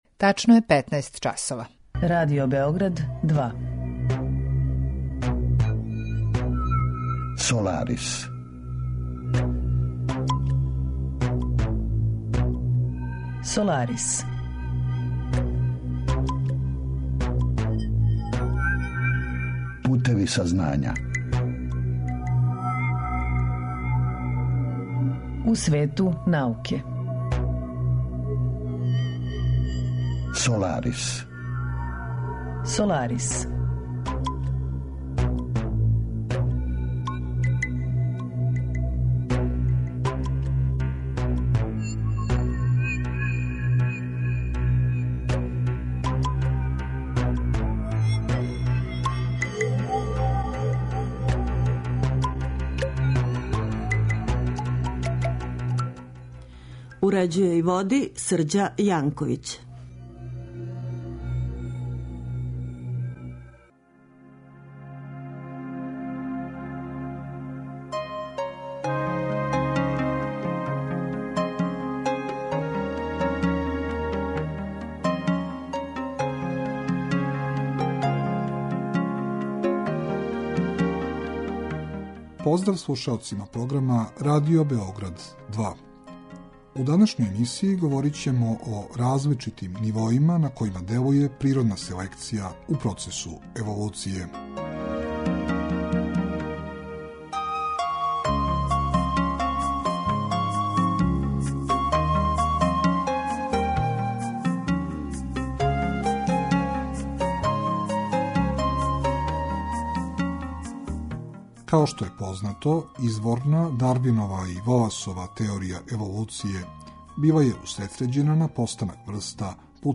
Разговор је први пут емитован 12. марта 2017.